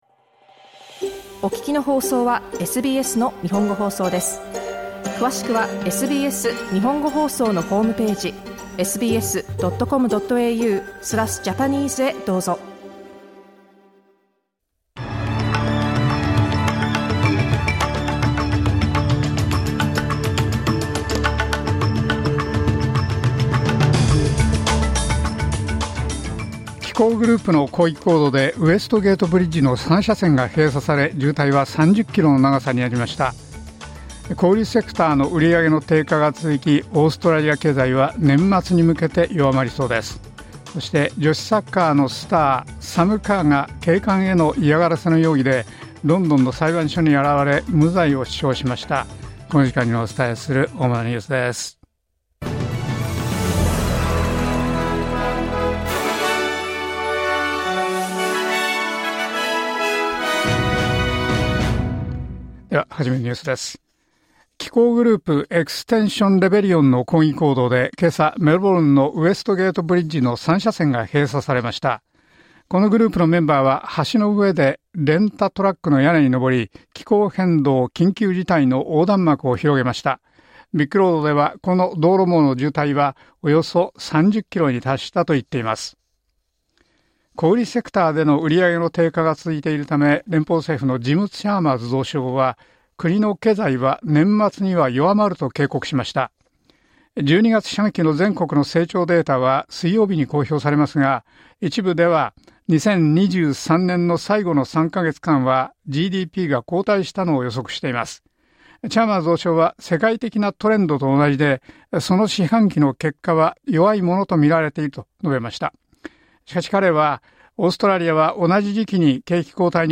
SBS日本語放送ニュース３月５日火曜日